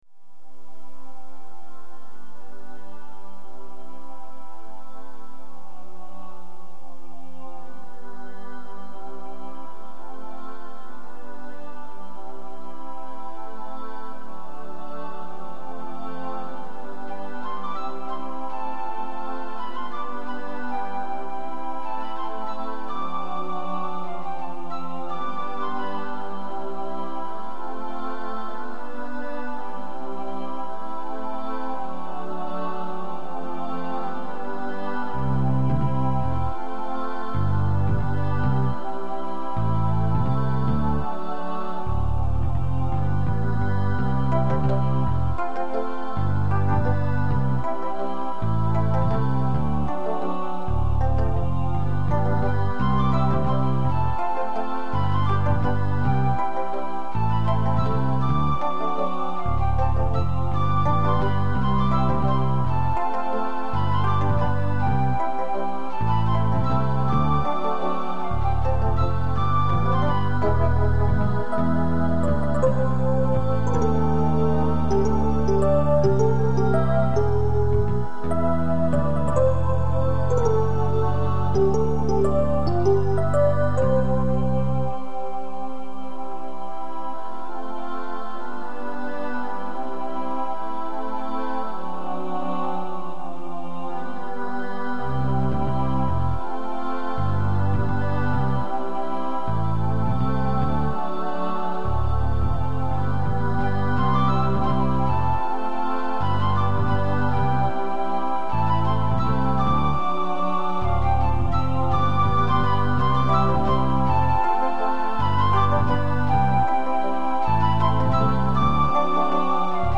Jul05, instrumental with a date, yet without a name.